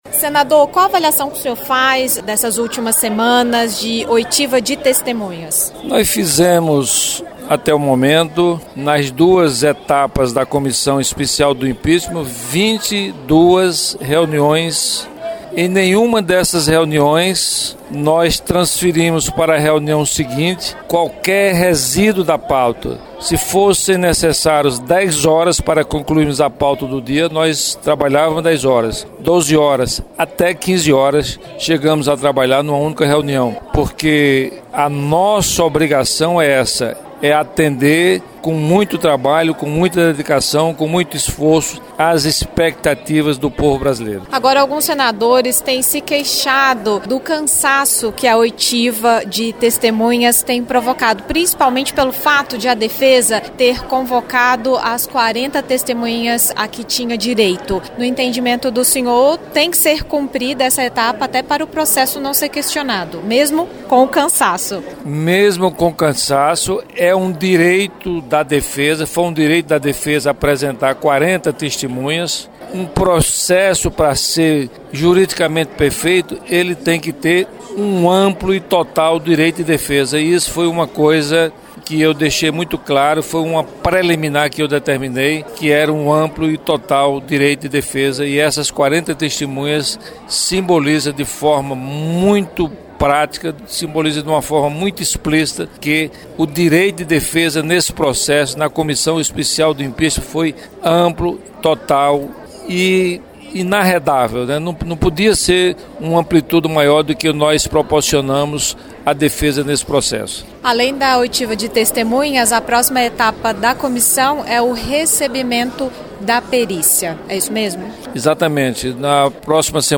Senado em Revista: Entrevistas – 24/06/2016